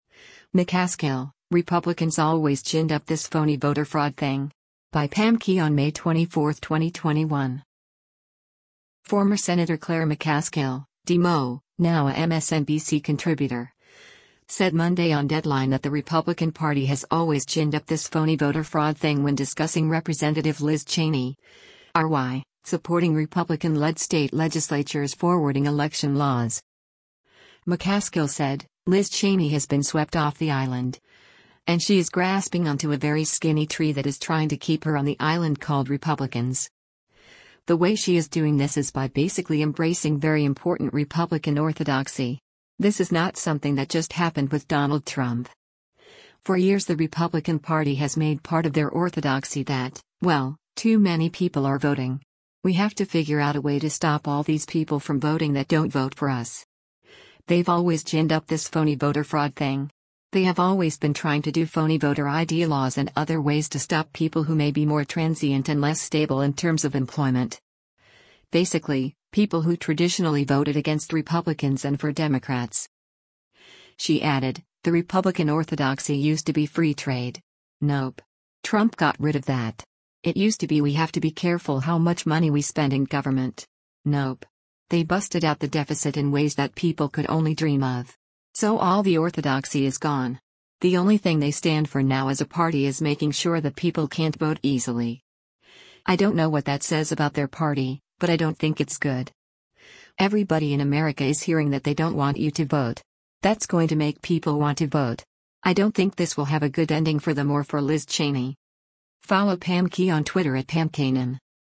Former Sen. Claire McCaskill (D-MO), now a MSNBC contributor, said Monday on “Deadline” that the Republican Party has “always ginned up this phony voter fraud thing” when discussing Rep. Liz Cheney (R-WY) supporting Republican-led state legislatures forwarding election laws.